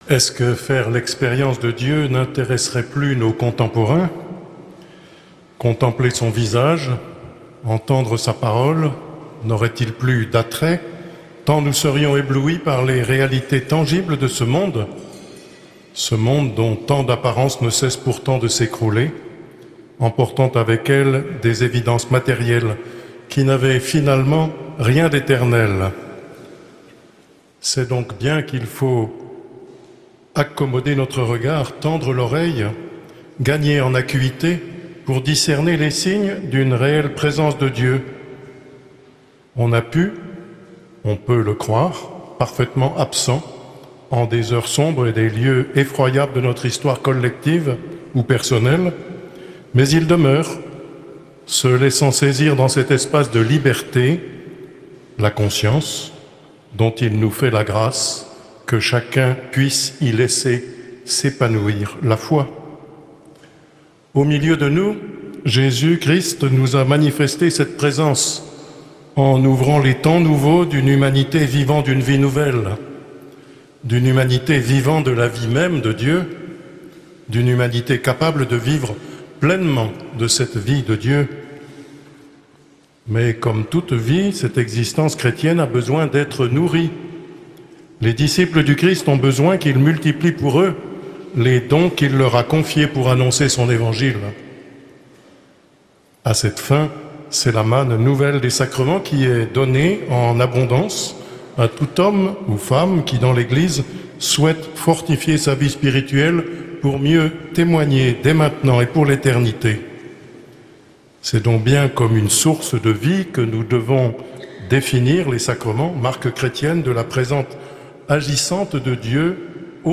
Accueil \ Emissions \ Foi \ Carême 2025 \ Conférences de carême De Notre-Dame de Paris.